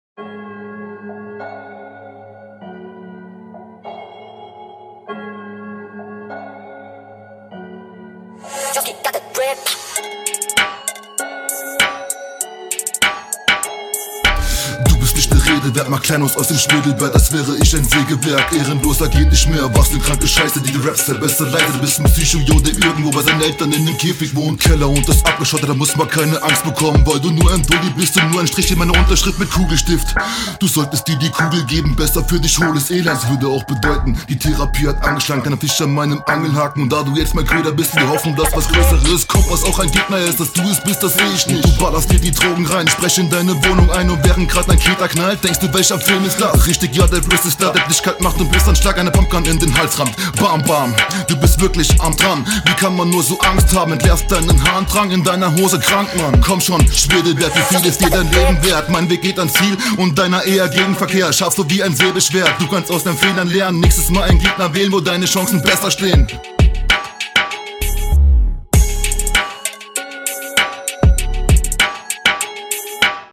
Flow: flow ist schon ganz rund gefällt mir Text: paar Zeilen dabei die ich ganz …
Die Runde ist die am besten geflowte im ganzen Battle.